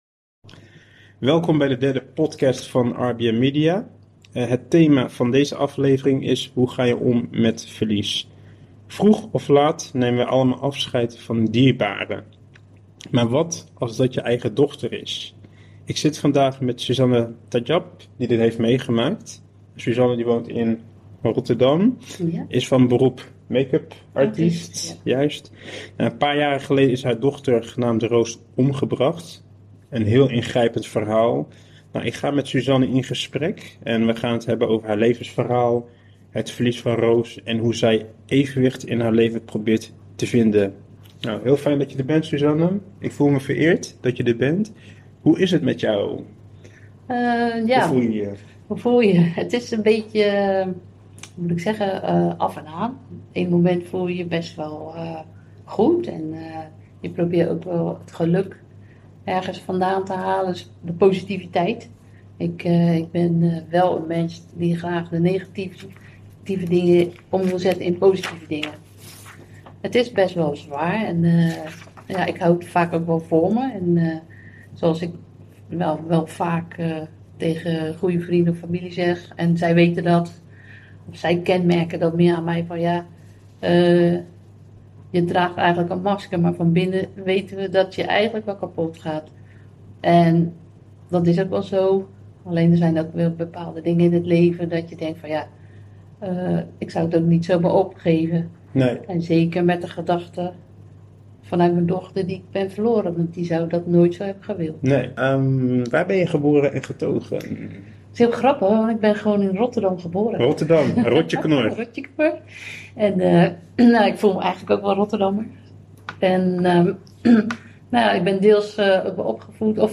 het eerste deel van het interview